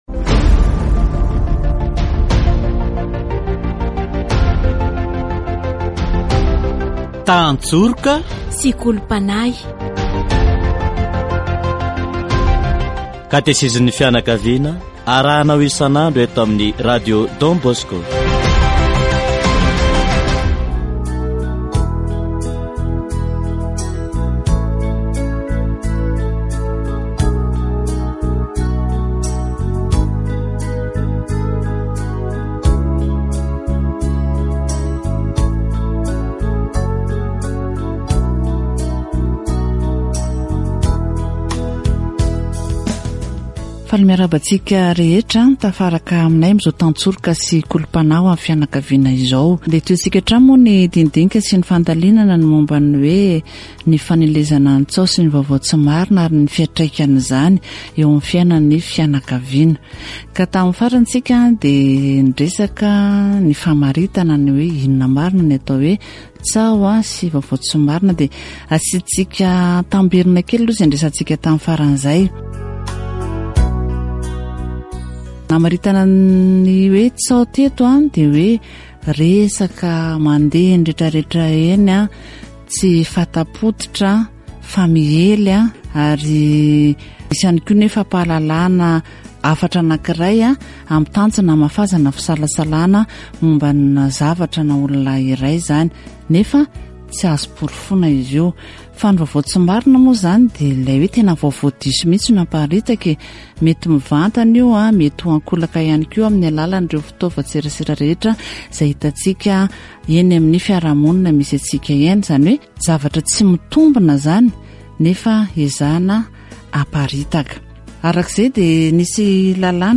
Les rumeurs se propagent loin et vite, une personne qui entend une rumeur la raconte aux autres. Catéchèse sur la propagation des rumeurs